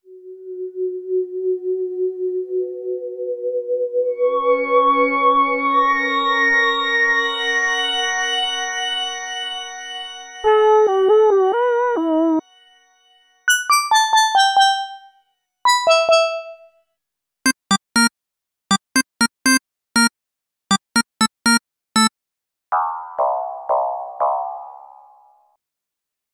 A short demo of instruments from the PLG150-DX.